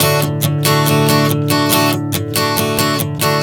Strum 140 D 03.wav